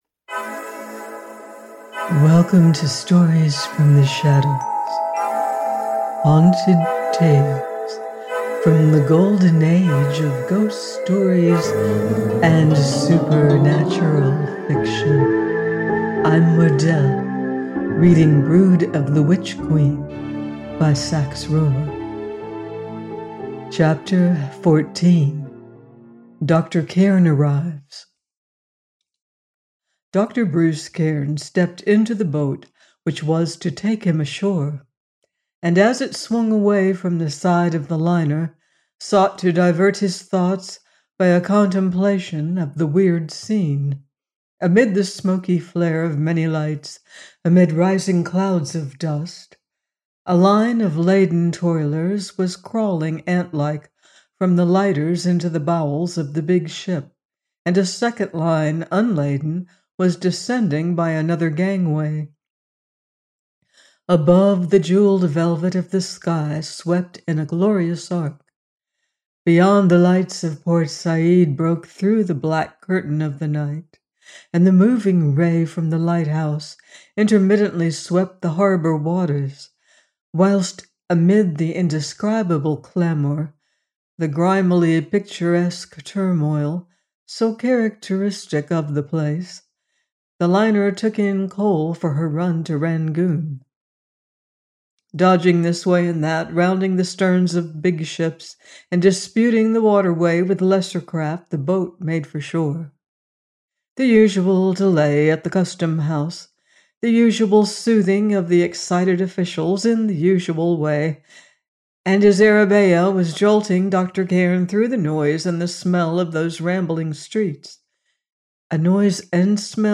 Brood of the Witch Queen – 14 : by Sax Rohmer - AUDIOBOOK